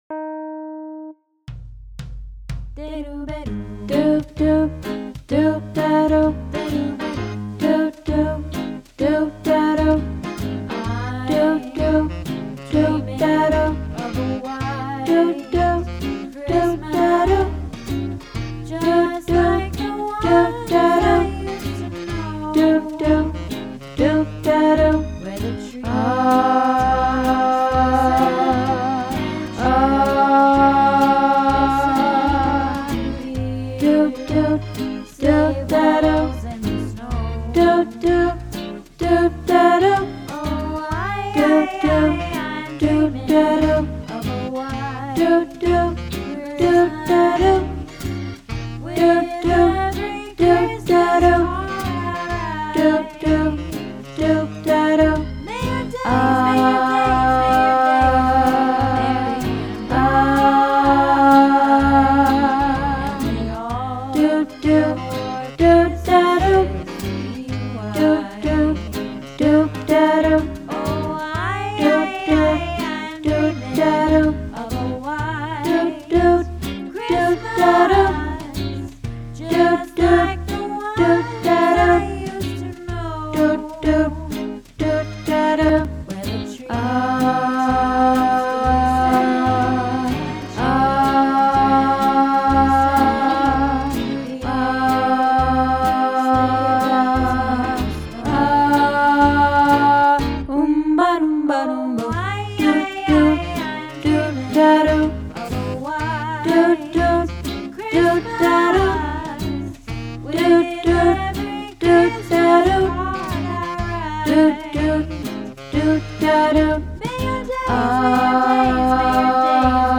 White Christmas - Tenor